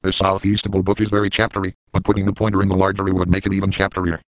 The Windows XP versions of Microsoft Mike, Mary and Sam.
Typing something like NEFULERYING will result in them saying "northeastfullerying." This creates endless possibilities for silly sentences.